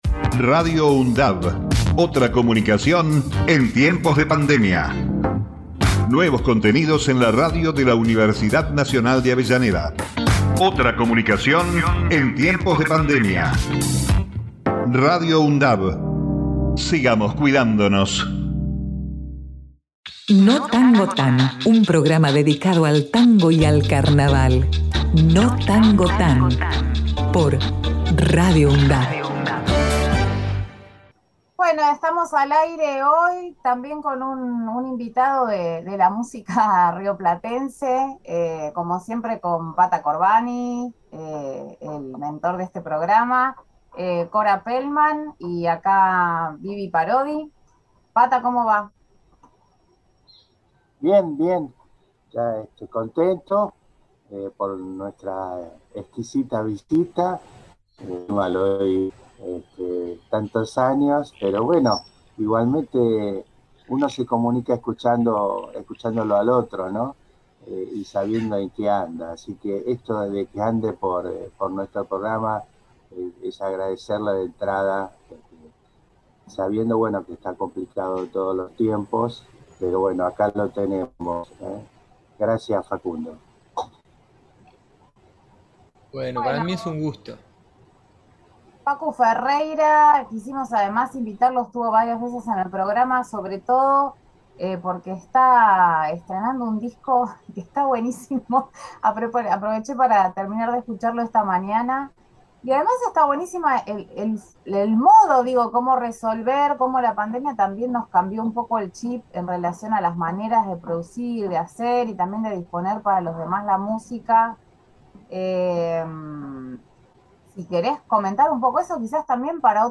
percusionista dedicado a la música sudamericana, presenta su nuevo disco.